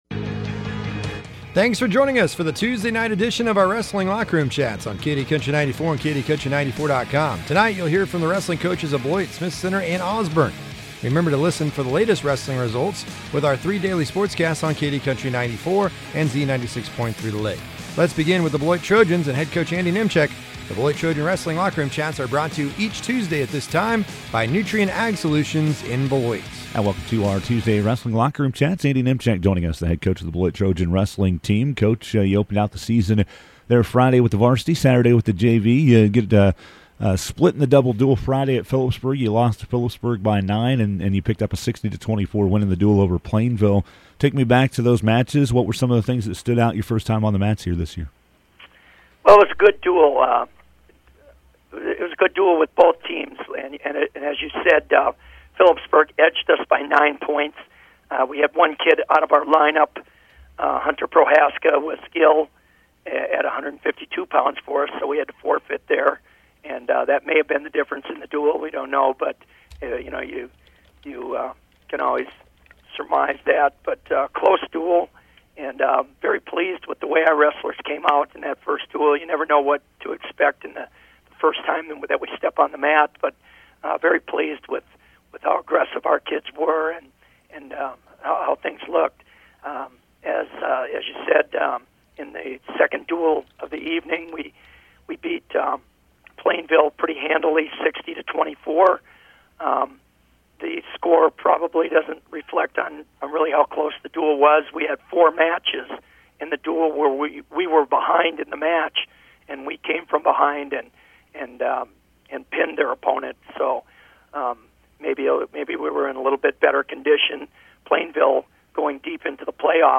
talks with the head wrestling coaches